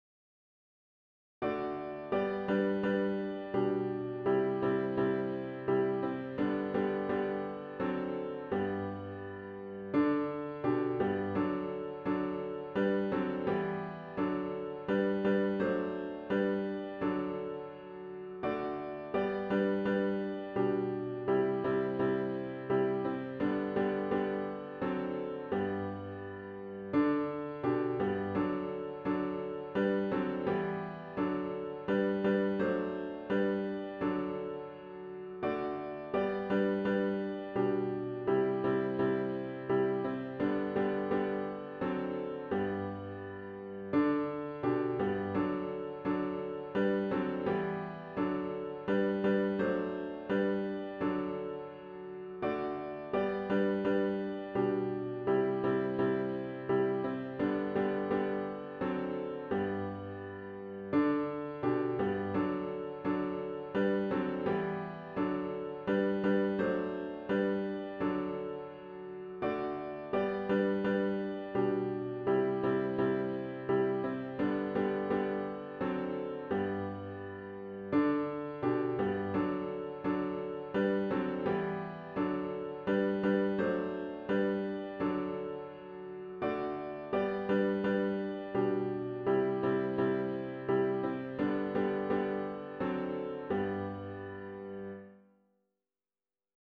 Sunday Jan 11, 2026 worship service
*OPENING HYMN “O for a Thousand Tongues to Sing” GtG 610